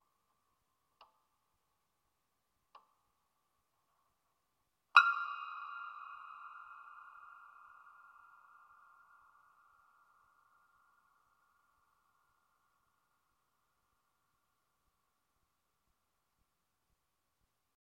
钢琴内的接触麦克风 " 钢琴内的接触麦克风扭曲
描述：用钢琴接触麦克风录制的嗡嗡声
标签： 内部钢琴 接触麦克风 鼻音
声道立体声